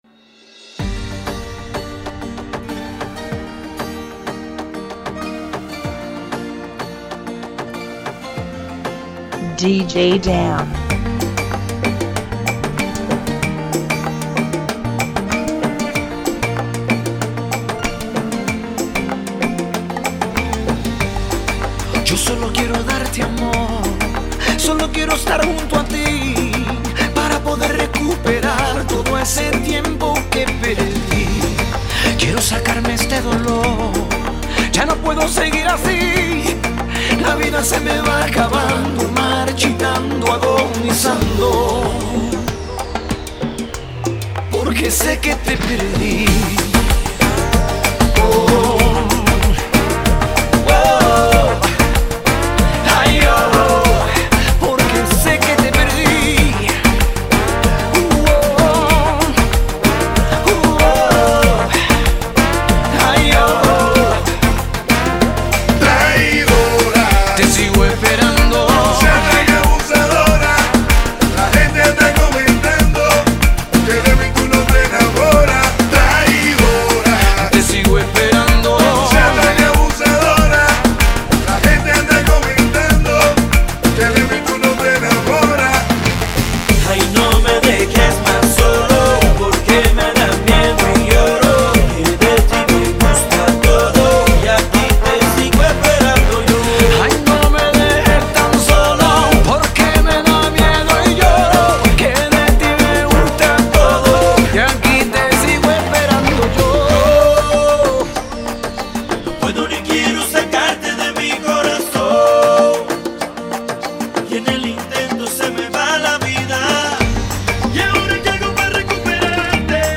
95 BPM
Genre: Salsa Remix